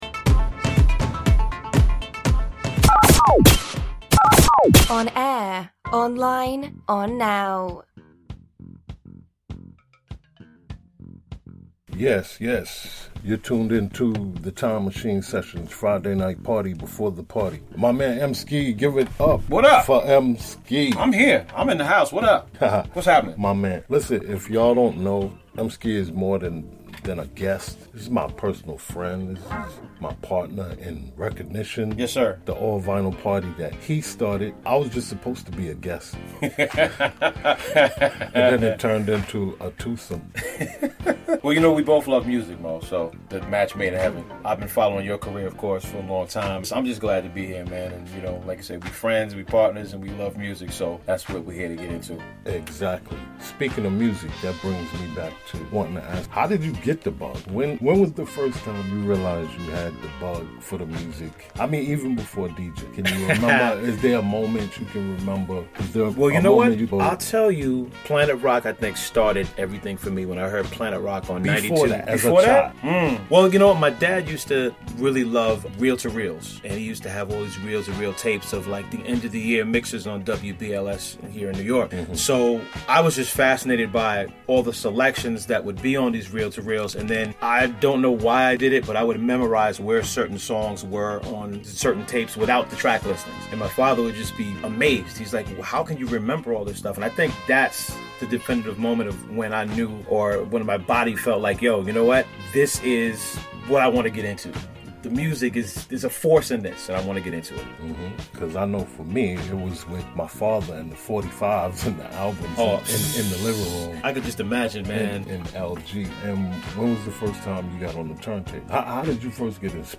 Aired Live on Friday, August 10th, 2018